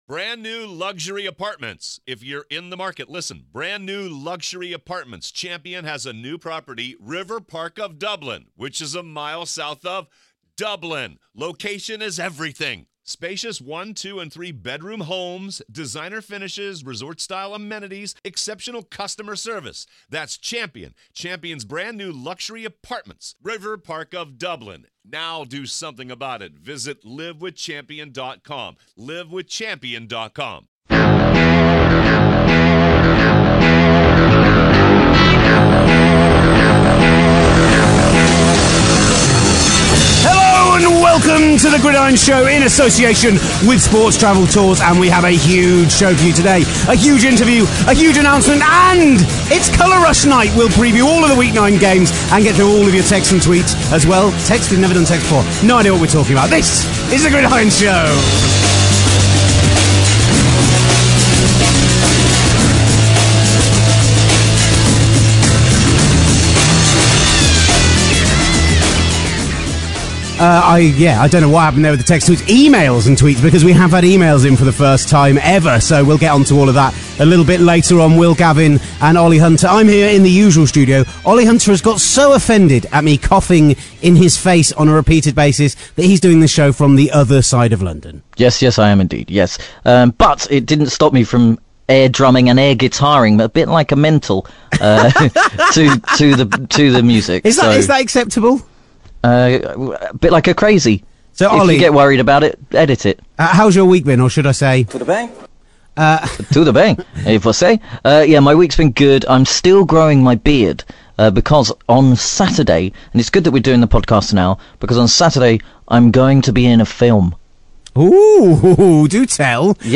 Panthers CB Josh Norman joins us on the phone to talk about his teams 8-0 start, his route into the NFL, how he has improved his game to an All Pro level and a little horse play too…